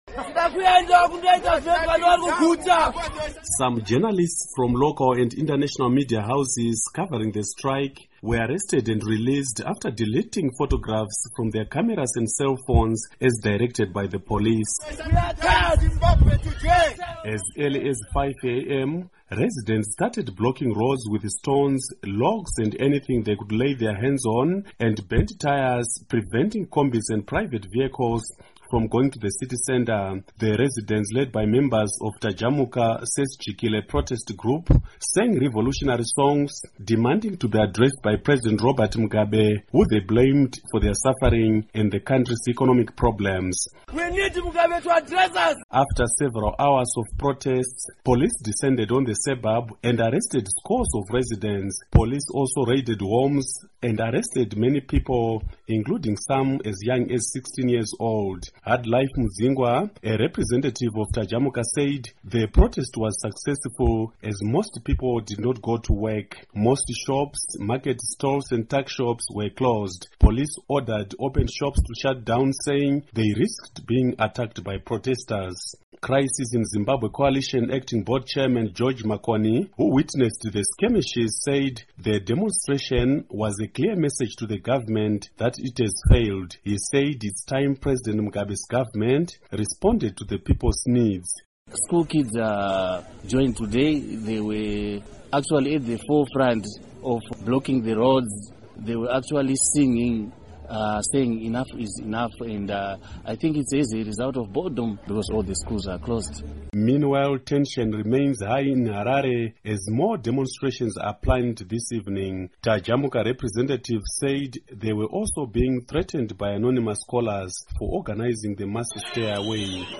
Report on Protests